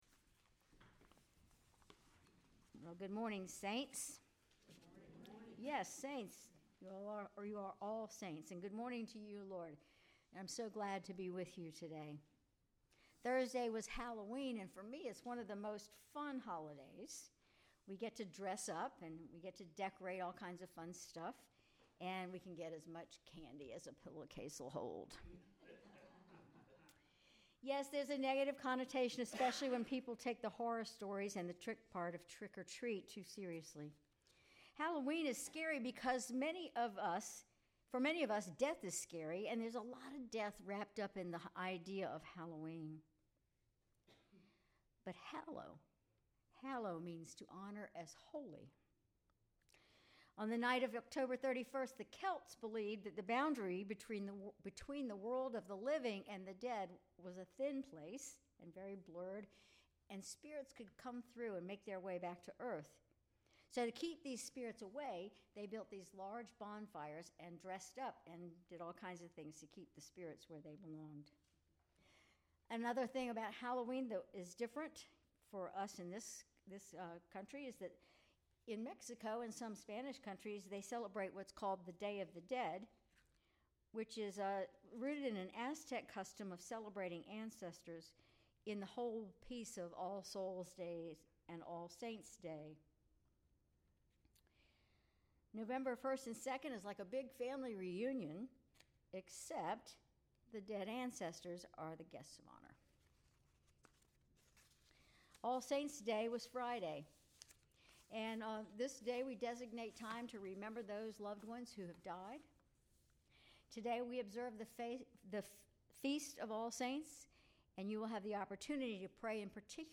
Sermon November 3, 2024
Sermon_November_3_2024.mp3